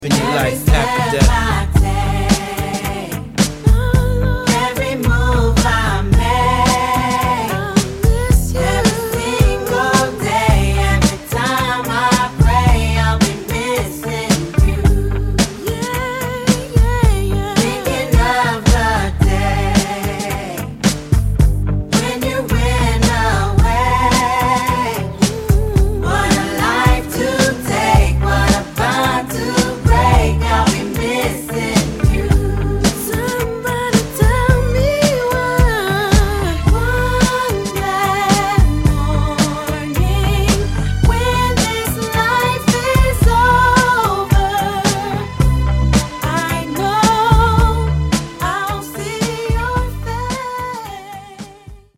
~* Pop *~